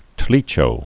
(tlēchō)